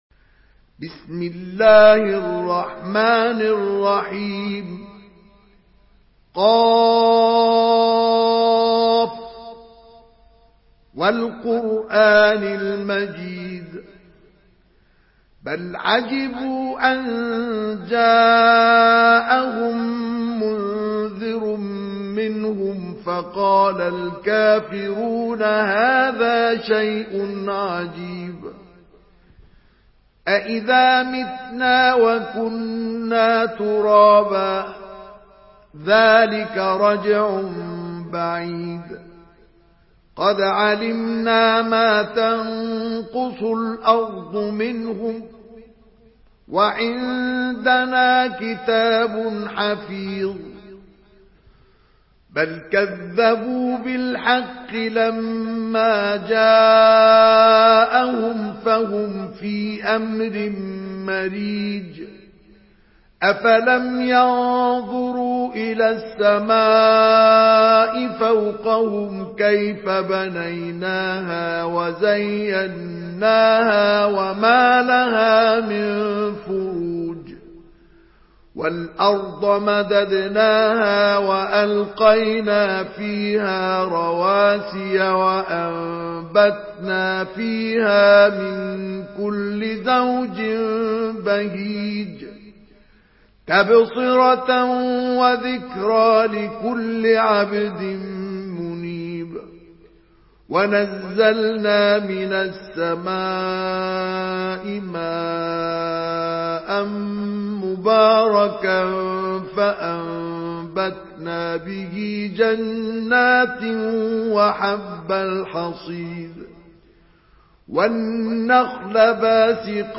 Surah Qaf MP3 in the Voice of Mustafa Ismail in Hafs Narration
Murattal